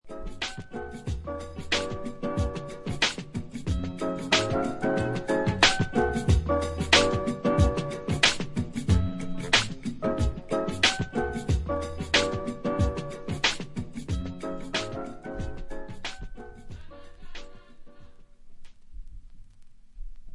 Soul Hip Hop